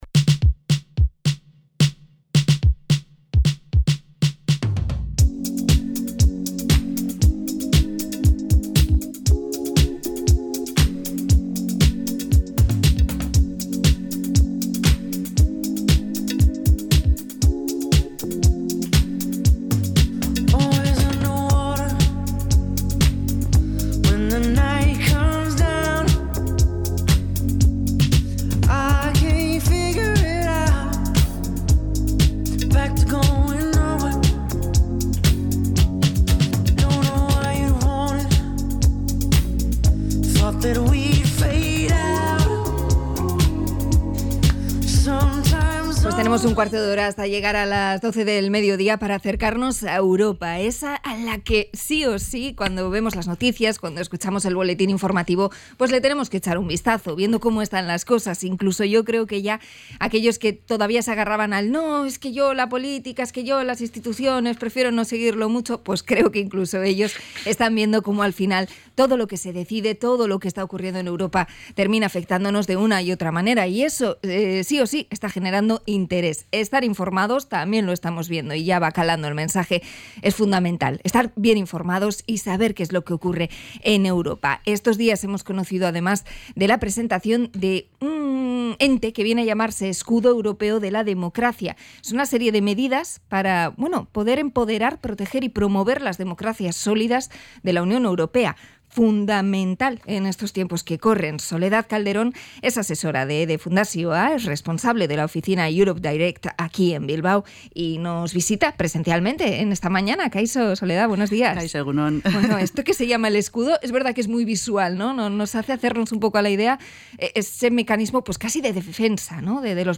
Visita mensual a la radio